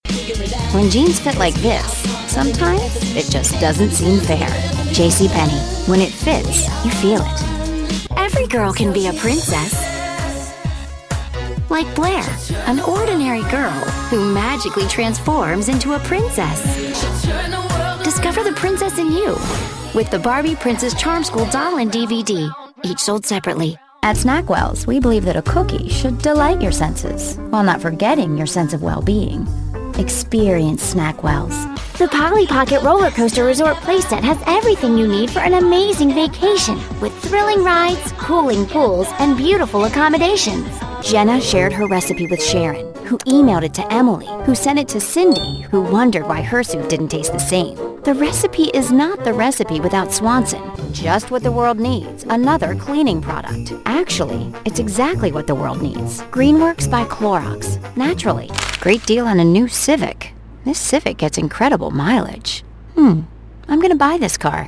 friendly, warm, sophisticated, inviting, breezy, tv spots, radio spots, elearning
mid-atlantic
Sprechprobe: Werbung (Muttersprache):